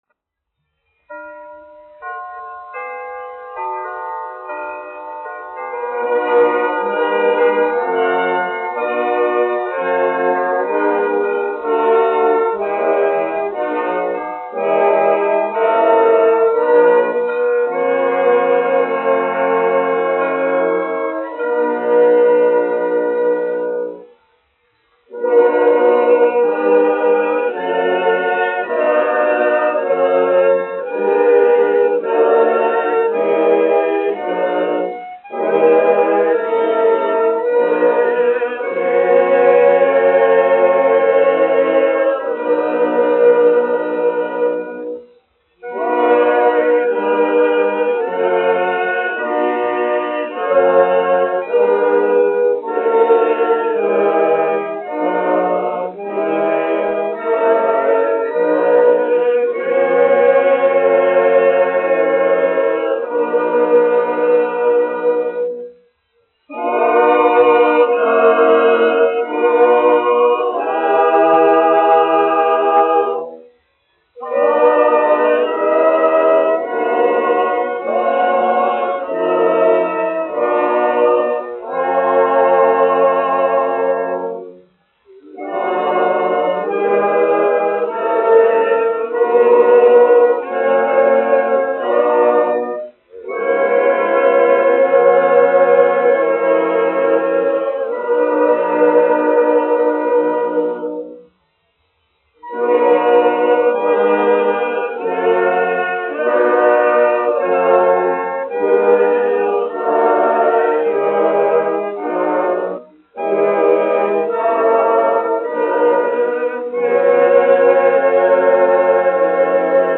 Königliche Hofoper (Berlīne, Vācija) Koris, izpildītājs
1 skpl. : analogs, 78 apgr/min, mono ; 25 cm
Garīgās dziesmas
Kori (jauktie)
Skaņuplate